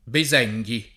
[ be @%jg i o be @$jg i ]